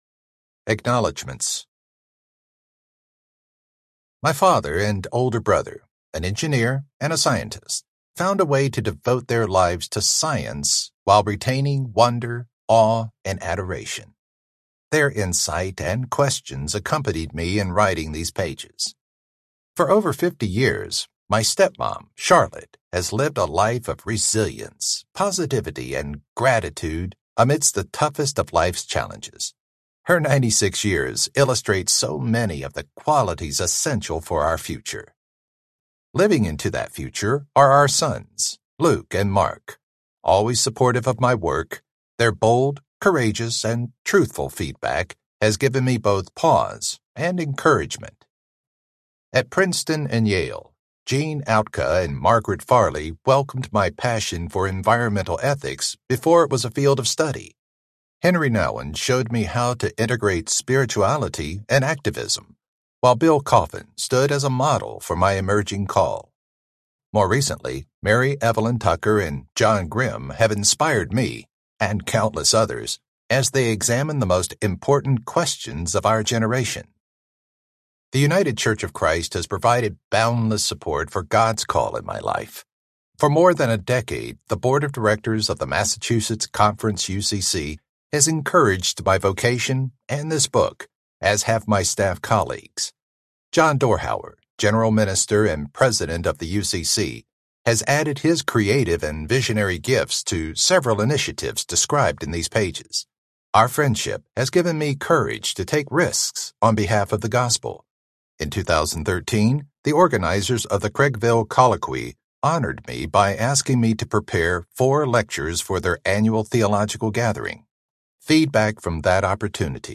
Climate Church, Climate World Audiobook
Narrator
7.5 Hrs. – Unabridged